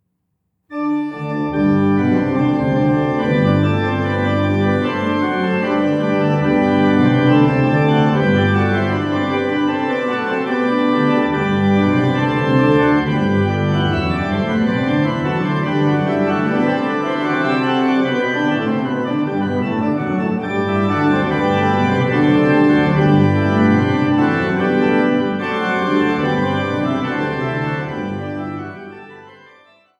Glatter-Götz-/Rosales-Orgel im Remter des Magdeburger Domes